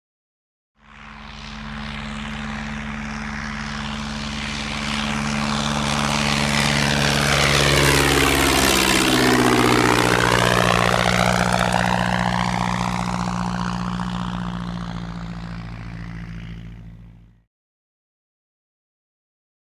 Tiger Moth|Ext|Fly By | Sneak On The Lot
Prop Plane; Fly By; Tiger Moth Prop Aircraft Flying Overhead Three Times.